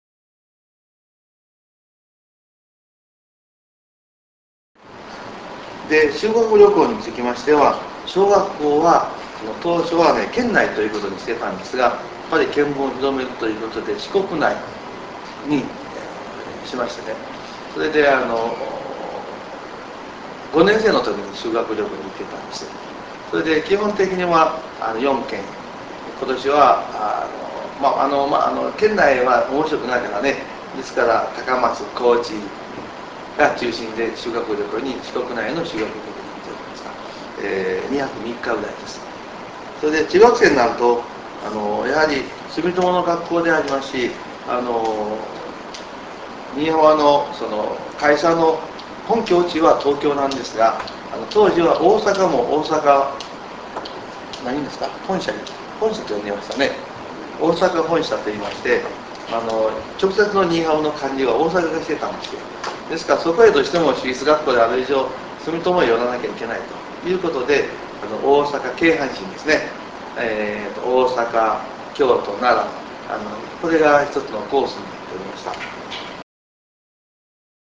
インタビュー ビデオをご覧いただくには、RealPlayerソフトが必要です。